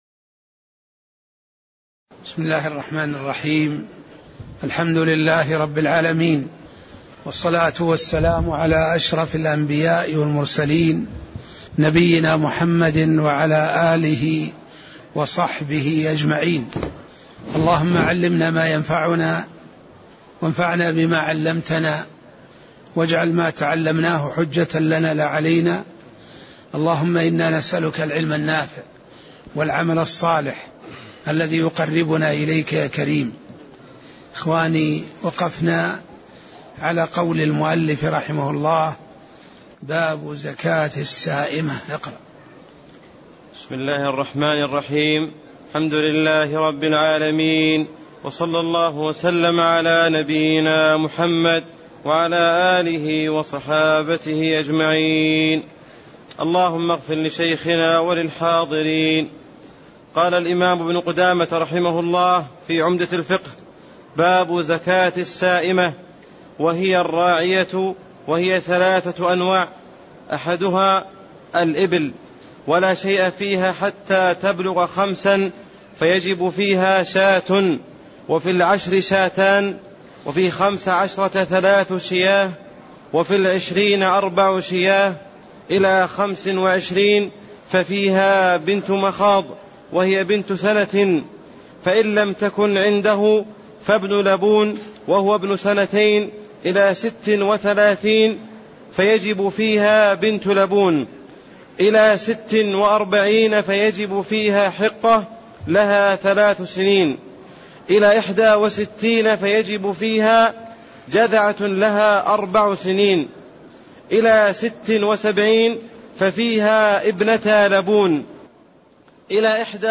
تاريخ النشر ٥ شعبان ١٤٣٤ هـ المكان: المسجد النبوي الشيخ